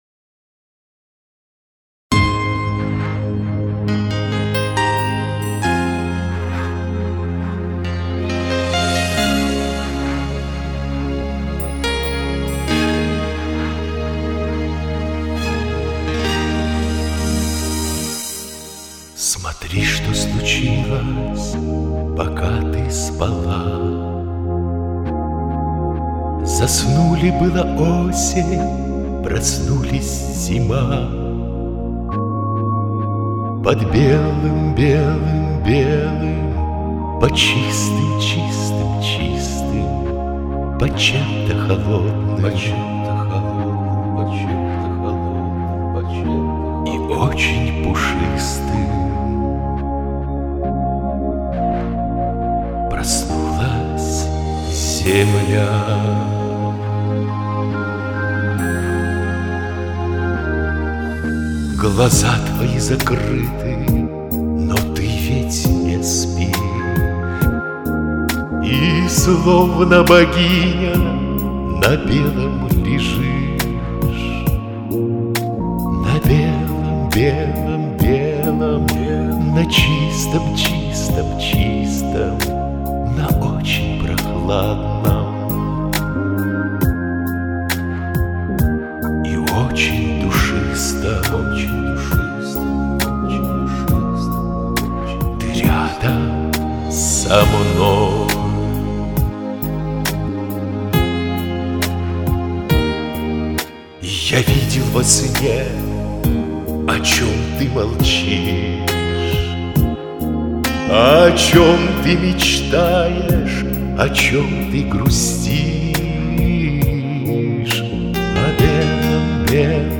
Оба спели нежно и вкрадчиво!